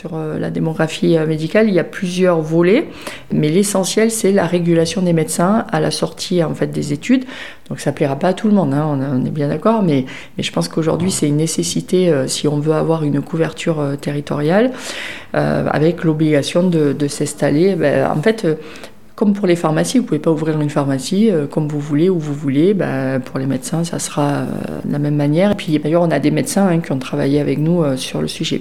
En ce début 2025, l’élue siégeant dans le groupe socialistes et apparentés a accordé une interview pour 48FM et tire un bilan de ces premiers mois en tant que parlementaire.